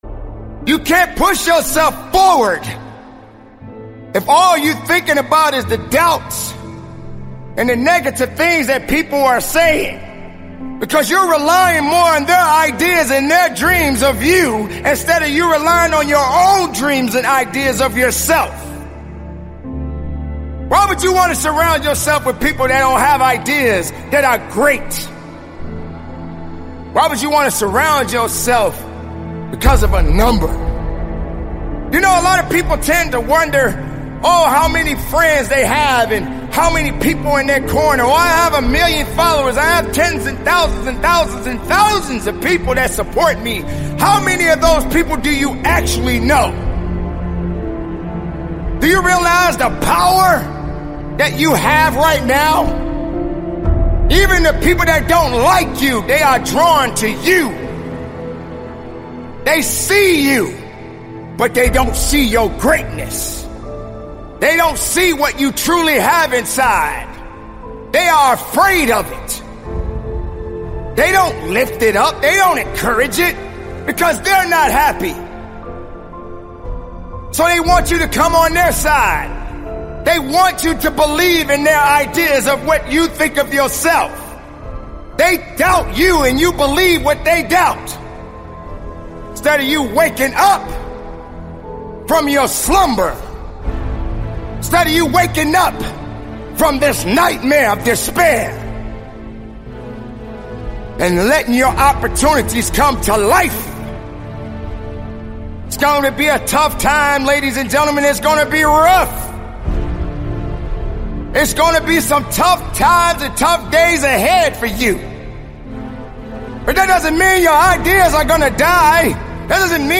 COMMITTED - The Most Powerful Motivational Speech Compilation For Success, Student & Working Out